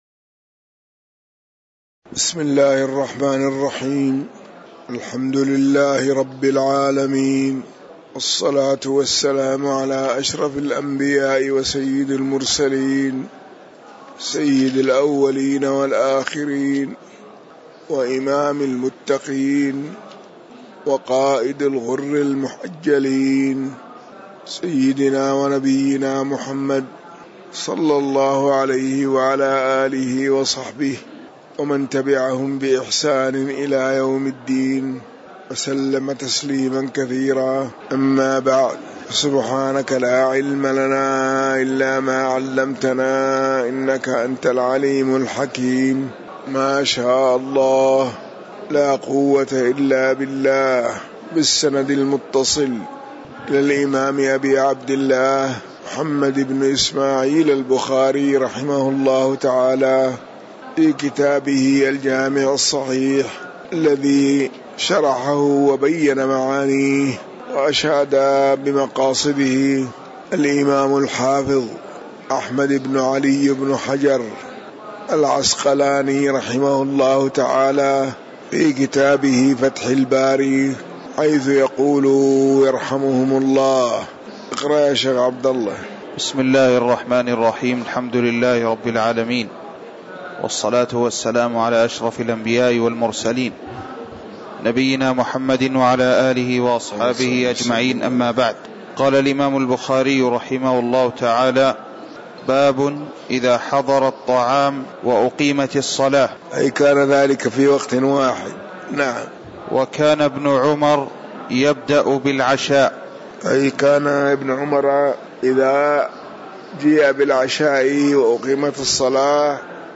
تاريخ النشر ٢٠ جمادى الأولى ١٤٤١ هـ المكان: المسجد النبوي الشيخ